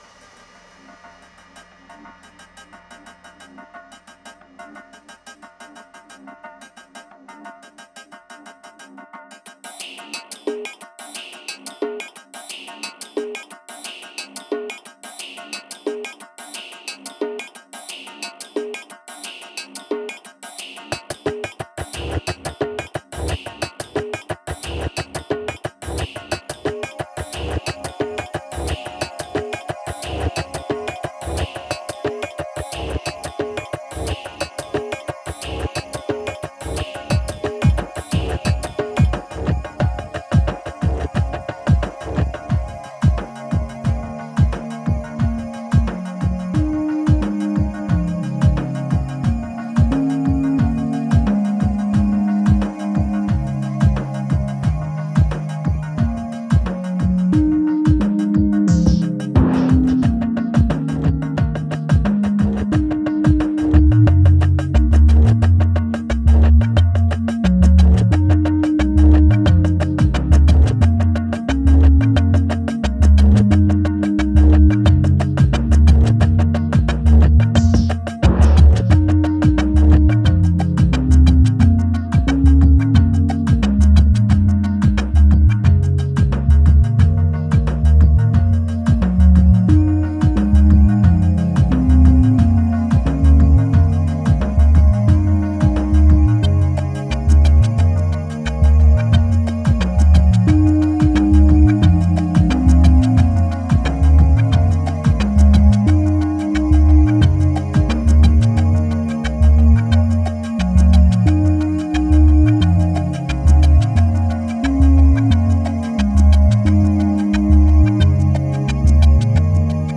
Genre: Psychill, Downtempo, IDM.